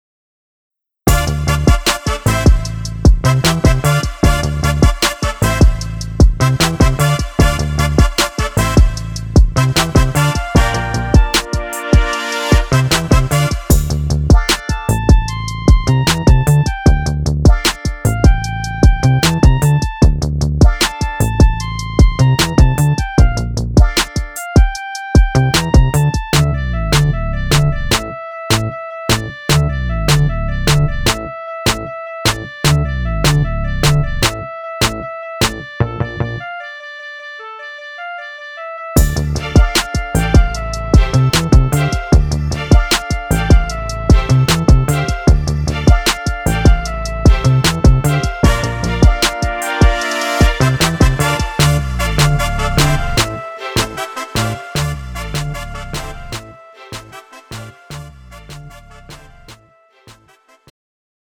음정 원키
장르 pop 구분 Pro MR